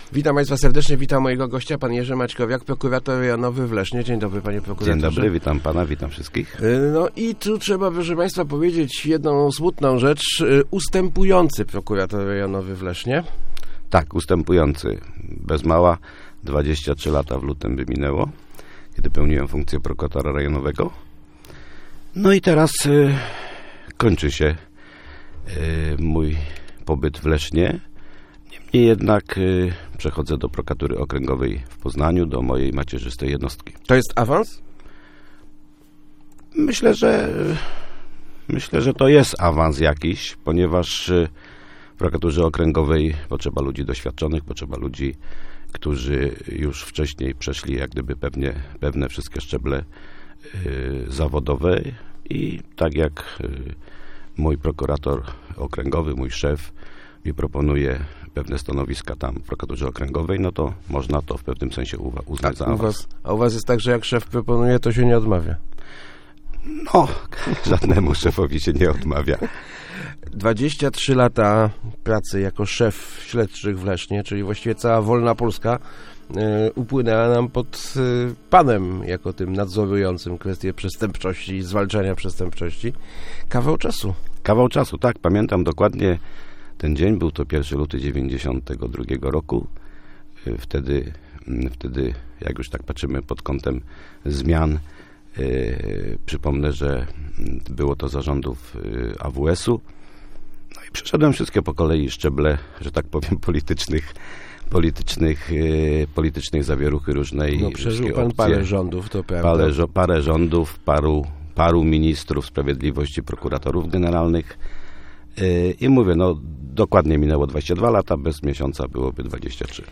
Jerzy Maćkowiak kończy właśnie 23-letnią pracę na stanowisku Prokuratora Rejonowego w Lesznie. Awansuje do Prokuratury Okręgowej w Poznaniu. -W tym czasie na szczęście w Lesznie nie było wielu szczególnie ciężkich przestępstw - mówił w Rozmowach Elki.